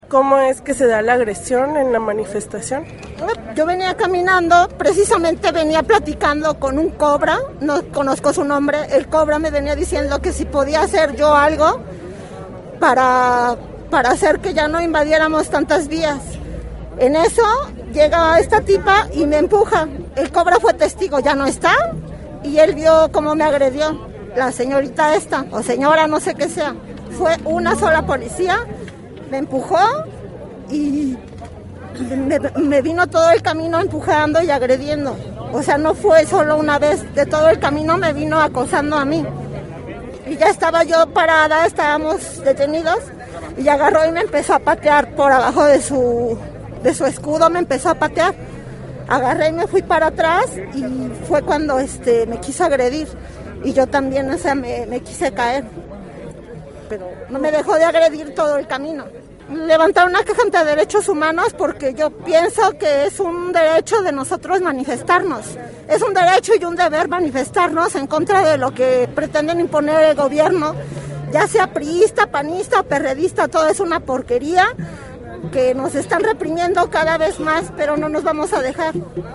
En entrevista con Regeneración Radio explica cómo se da la agresión.